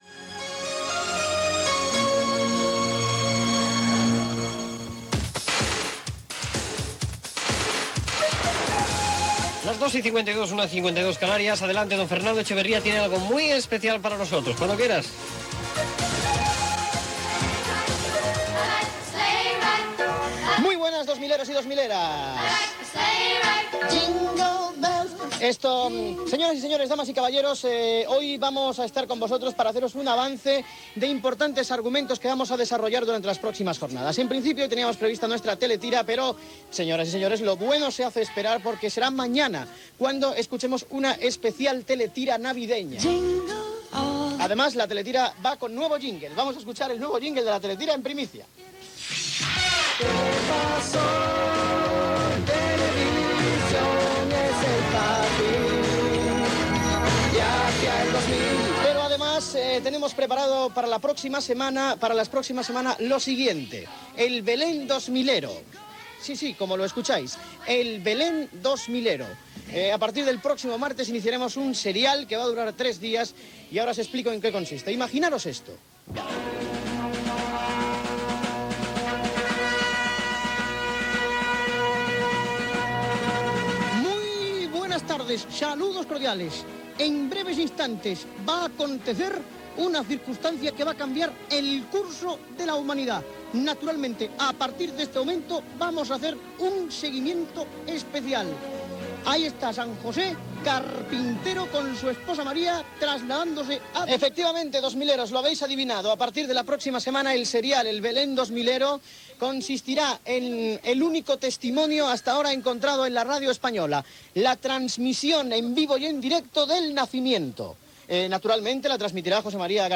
Hora, novetats de la seccio a partir de la propera setmana (amb la imitació de José María García i Johan Cruyff ), cançó, avís que el programa no té cap fotografia amb tot l'equip que el fa, comiat
Entreteniment